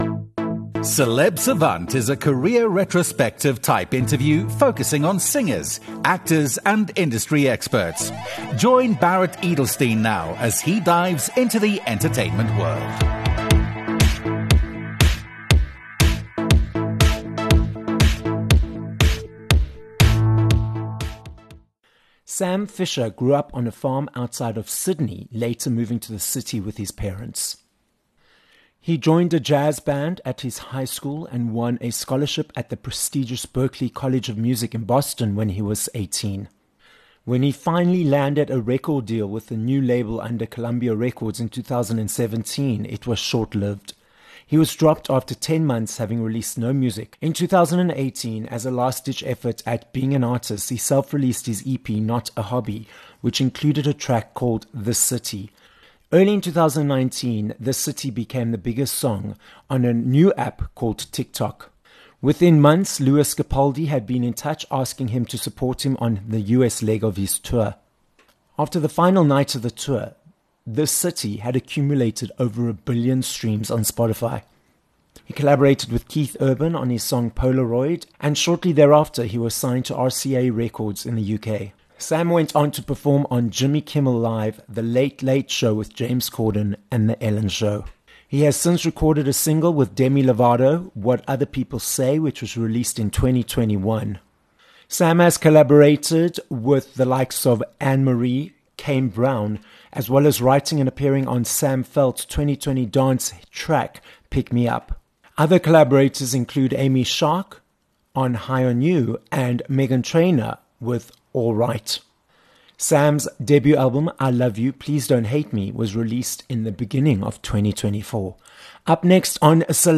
12 Mar Interview with Sam Fischer